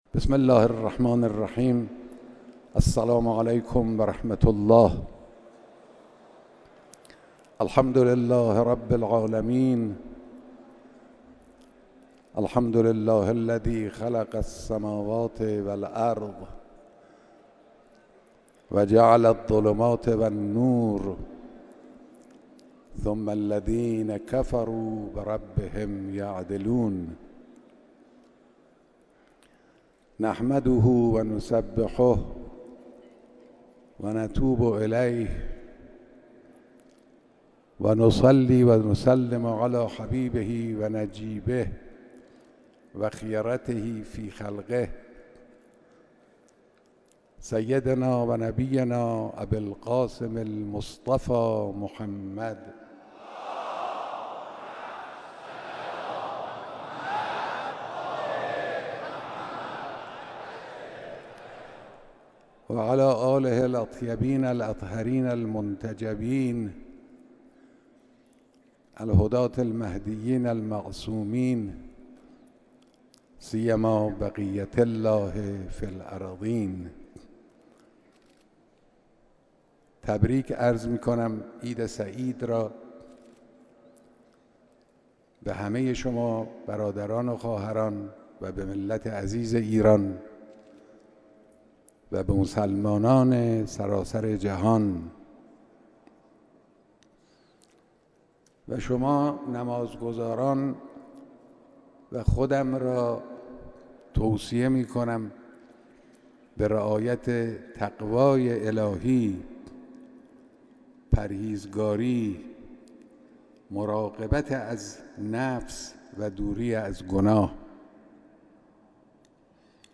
بیانات در خطبه های نماز عید سعید فطر